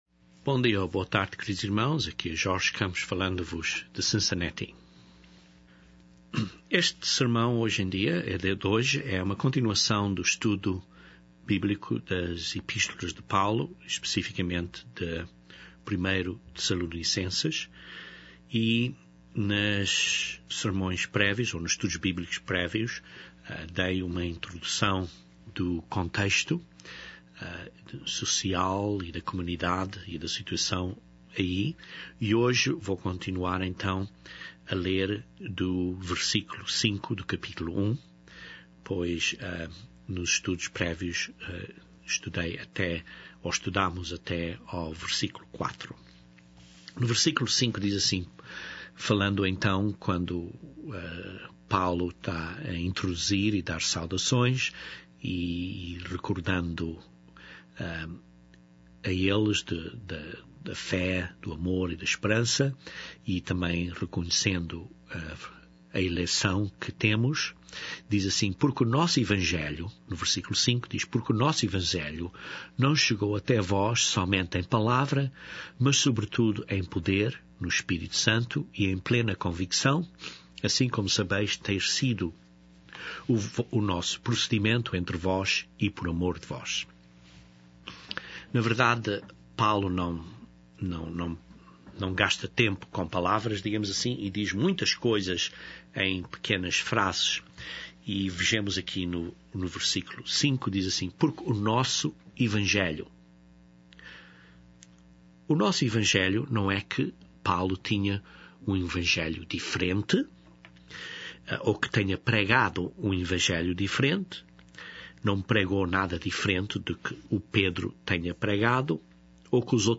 Este estudo bíblico continua o estudo de 1 Tes de 1:5 até 2:4.